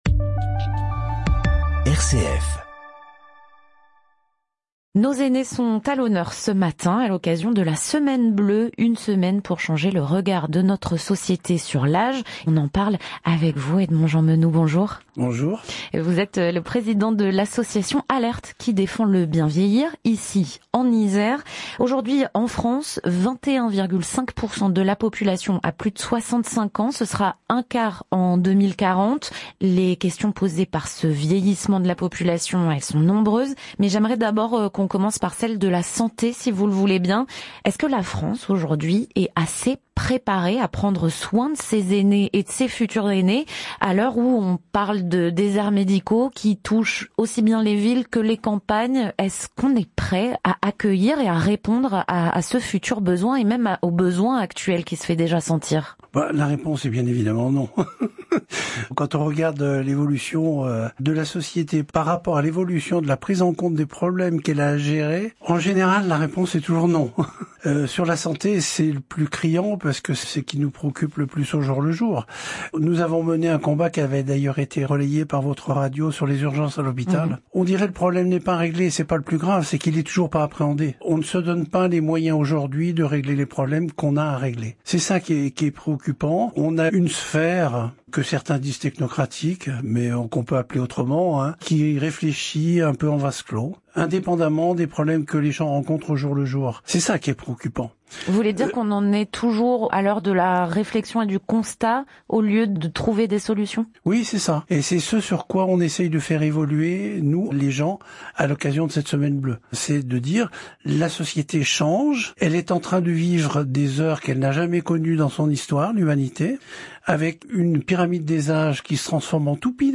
Semaine Bleue 2025 : Interview RCF
Nous avons été interviewé dans le cadre de la semaine bleue par la radio RCF.